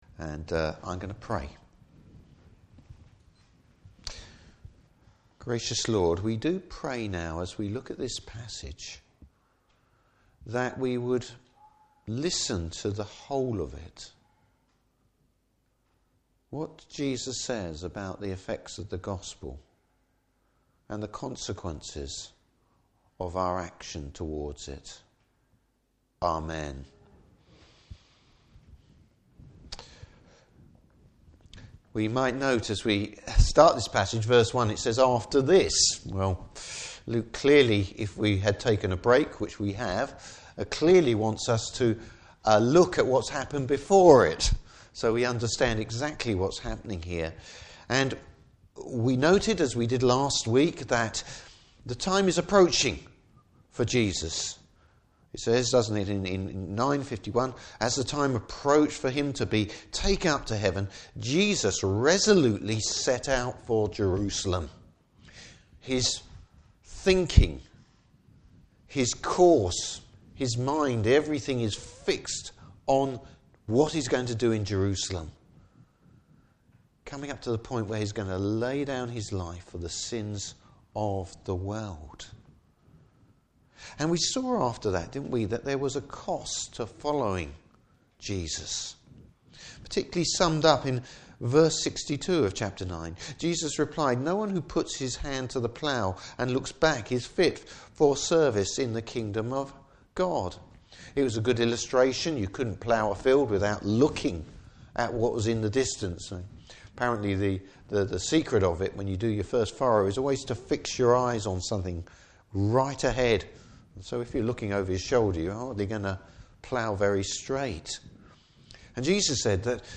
Service Type: Morning Service Bible Text: Luke 10:1-16.